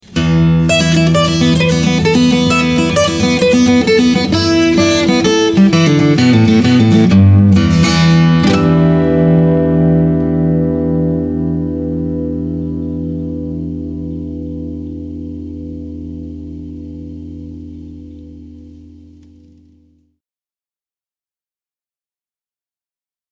12 string Jumbo Euro Spruce/Red Gum, now with sound
But this one all is just right.
It looks satin so you expect a walnut like sound, but it has an excellent good clear ring to it.
BTW: the sustain of the sound seems manupulated. But honestly, this thing rings forever.